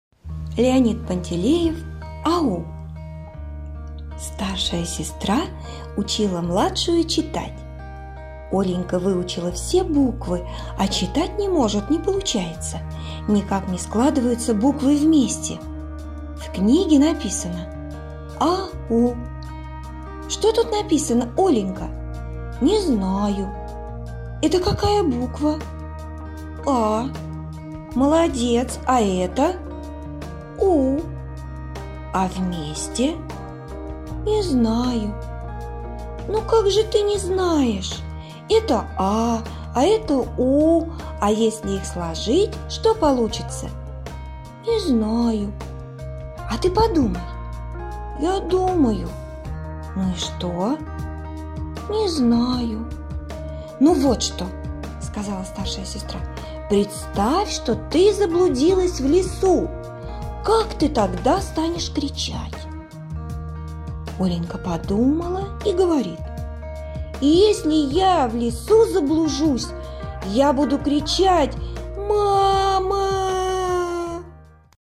Аудиорассказ «АУ»